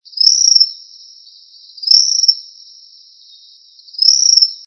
Tropical Kingbird (Tyrannus melancholicus)
Location or protected area: Reserva Ecológica Costanera Sur (RECS)
Condition: Wild
Certainty: Photographed, Recorded vocal
Recs.Suiriri-real.mp3